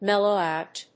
méllow óut